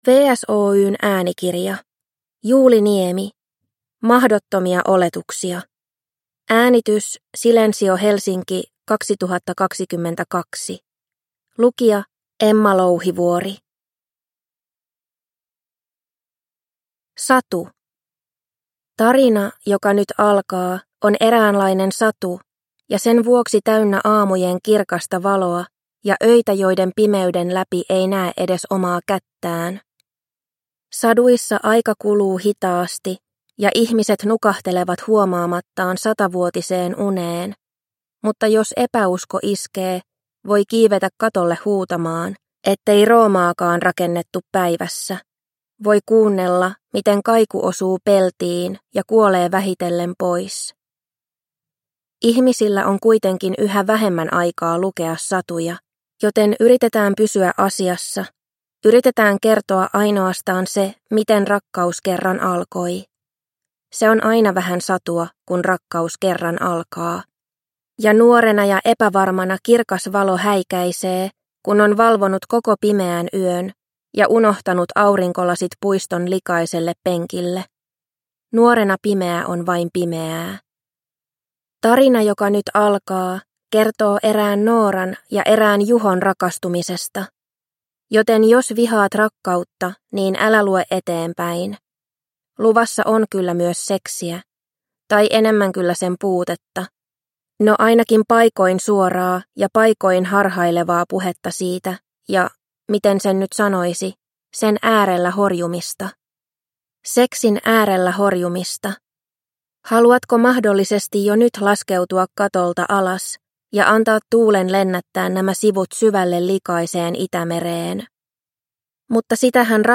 Mahdottomia oletuksia – Ljudbok – Laddas ner